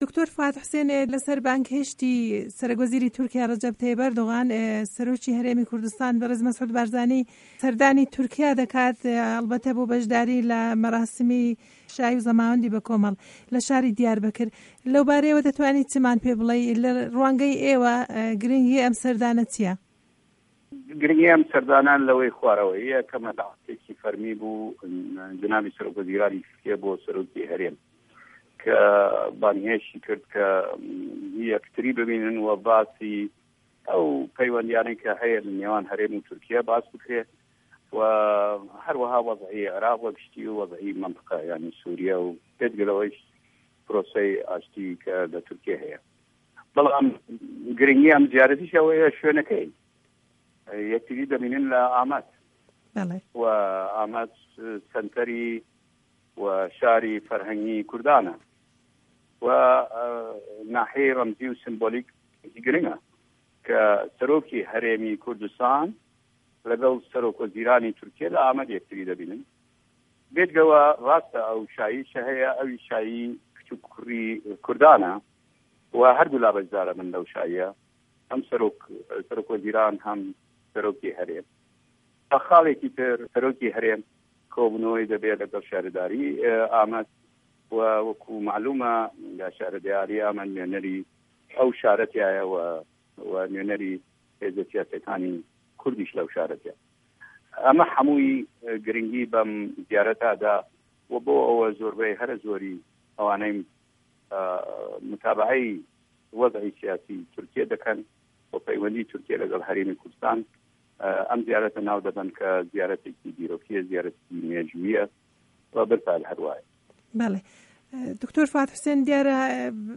وتووێژ له‌گه‌ڵ دکتۆر فواد حسێن